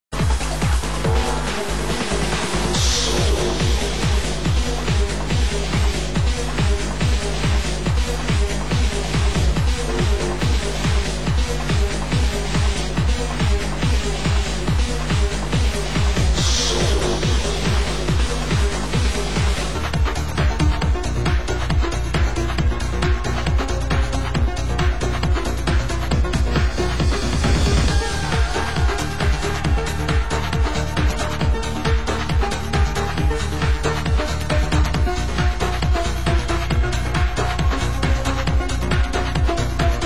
Genre: Hard House